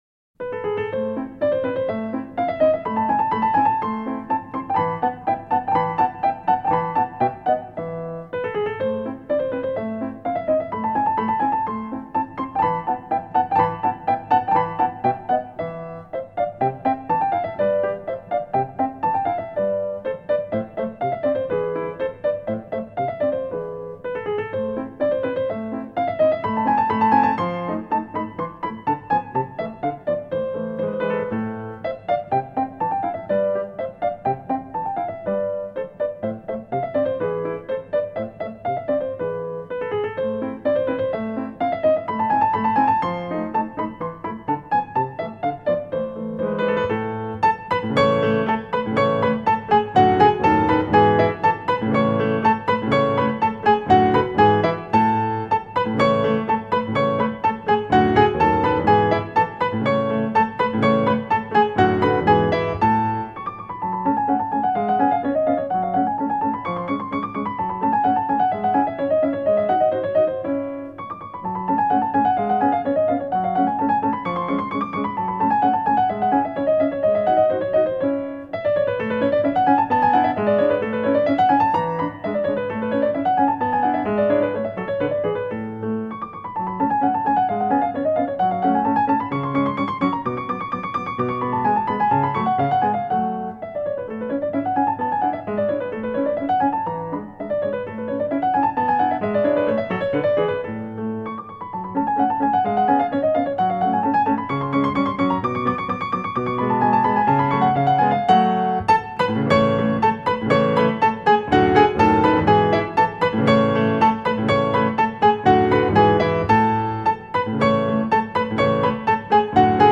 قطعه موومان سوم سونات پیانو شماره ۱۱
موسیقی اینسترومنتال موسیقی بیکلام
موسیقی کلاسیک